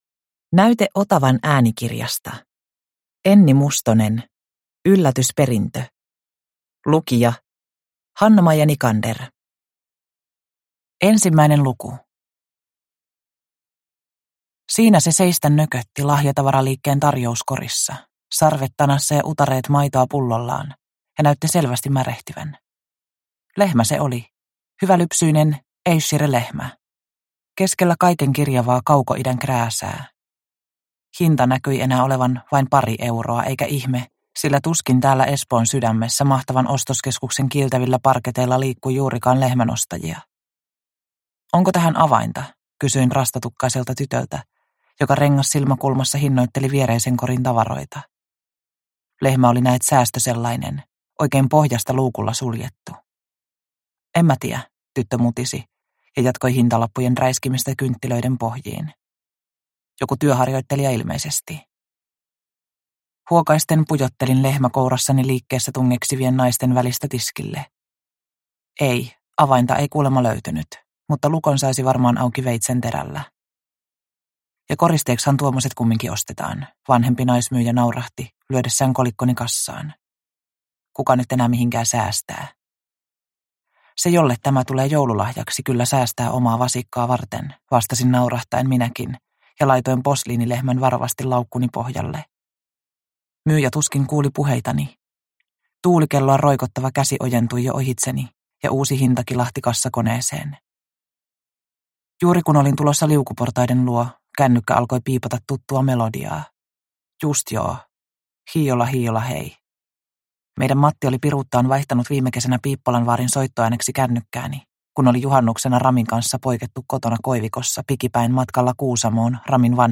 Yllätysperintö – Ljudbok